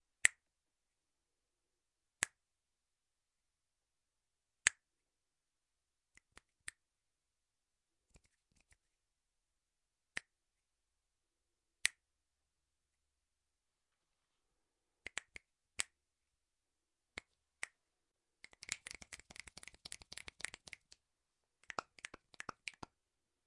手指卡住 点击
描述：手指对齐并点击。各种 单击和倍数 装备：在带有EV 635A麦克风的演播室环境中使用SONY PCMM10录制。